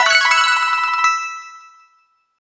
}} Category:Super Mario Maker 2 sound effect media files You cannot overwrite this file.